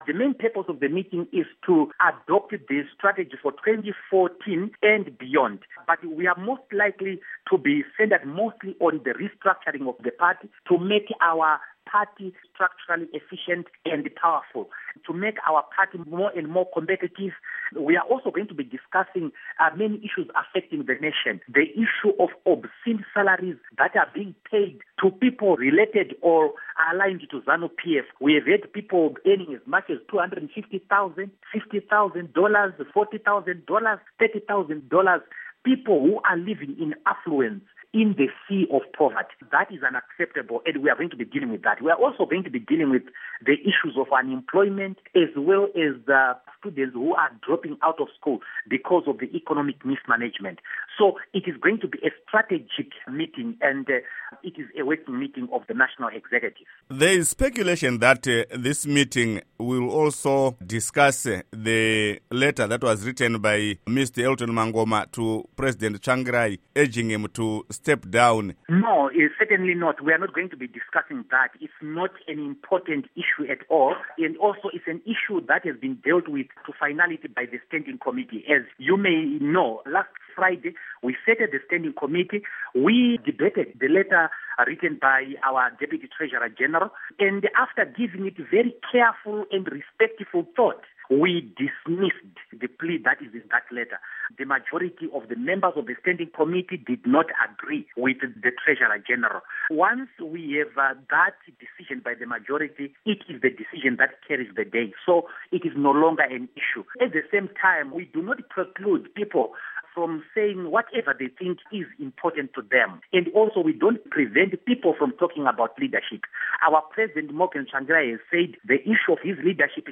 Interview With Douglas Mwonzora